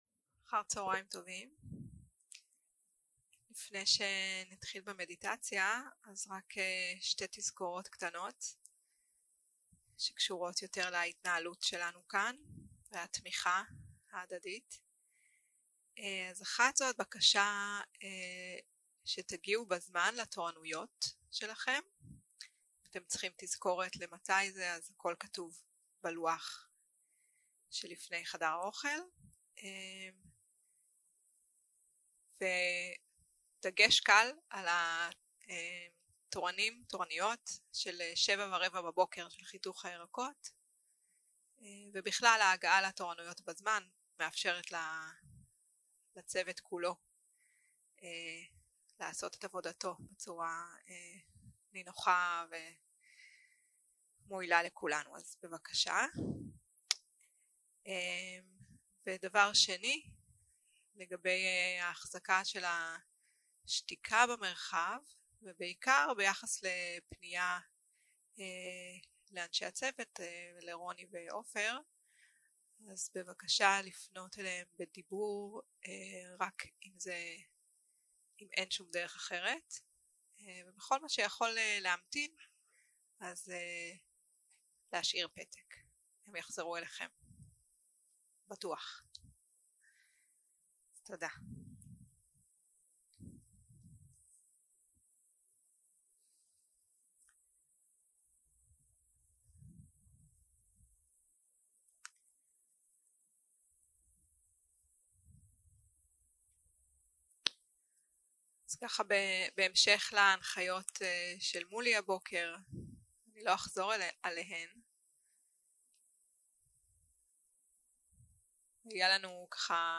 יום 2 - הקלטה 3 - צהרים - מדיטציה מונחית